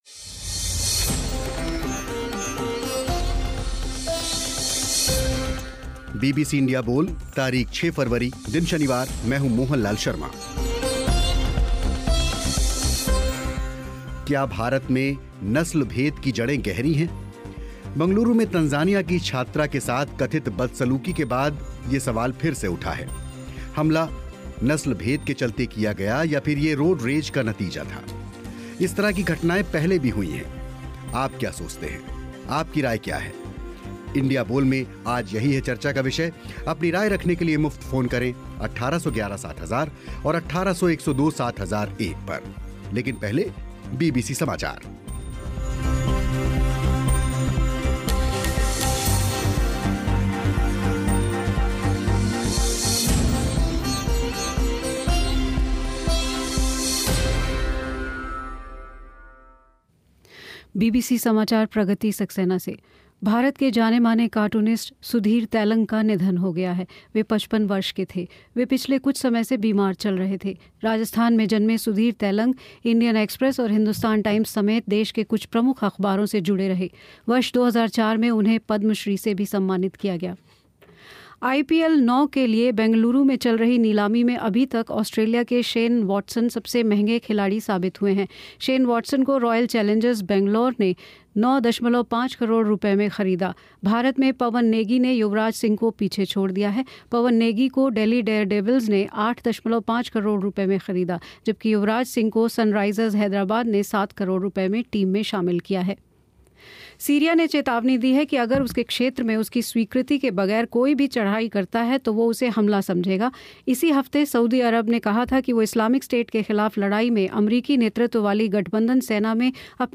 बंगलौर में तंज़ानिया की छात्रा से कथित बदसलूक़ी के बाद फिर उठा ये सवाल. बीबीसी इंडिया बोल में इसी पर हुई चर्चा.